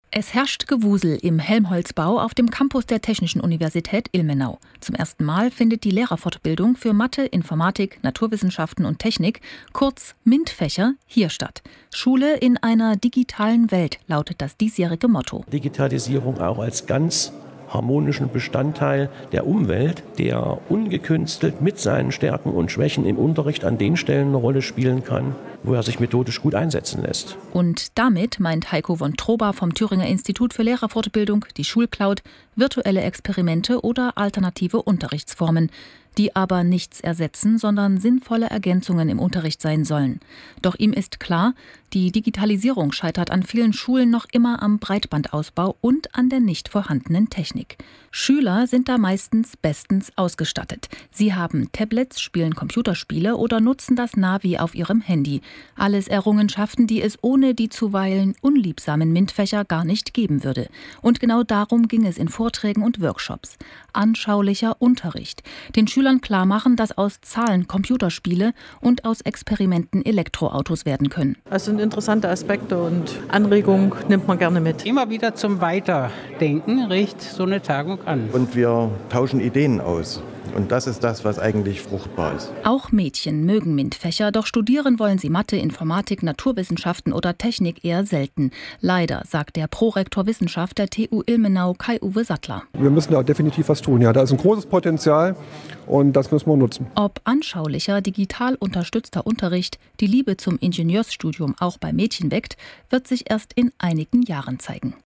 zum Radiobeitrag